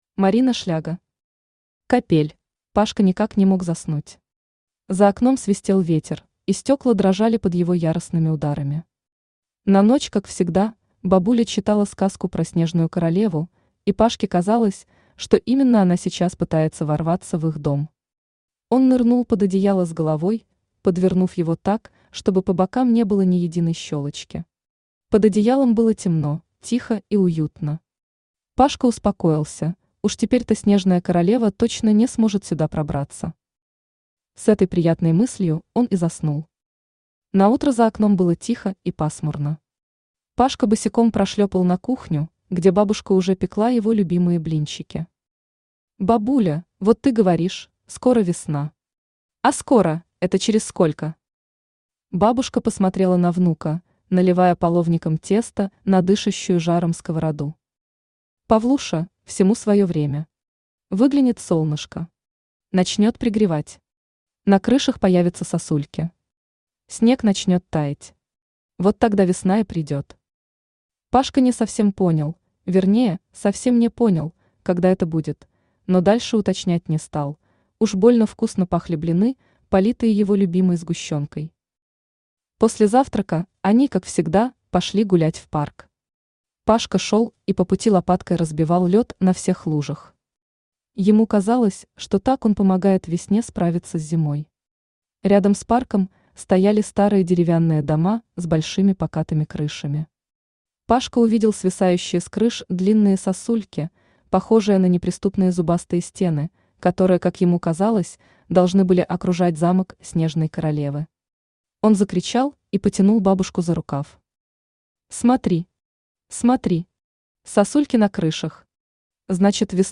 Аудиокнига Капель | Библиотека аудиокниг
Aудиокнига Капель Автор Марина Шляго Читает аудиокнигу Авточтец ЛитРес.